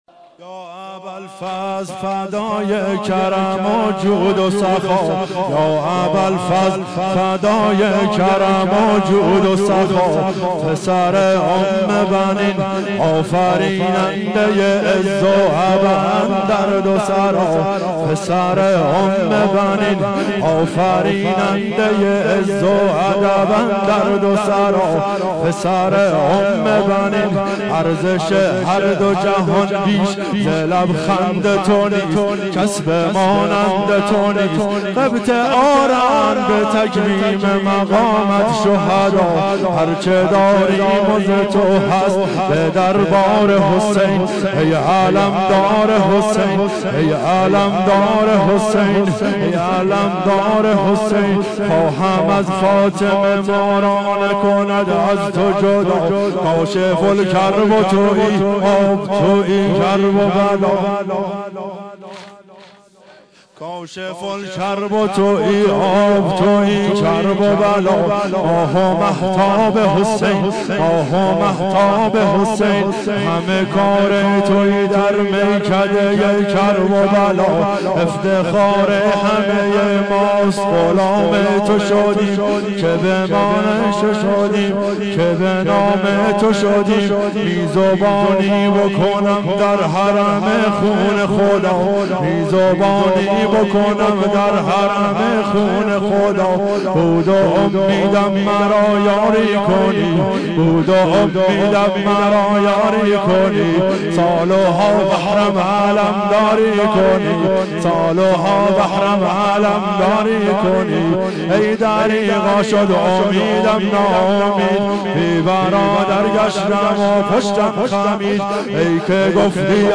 واحد شب تاسوعا 1391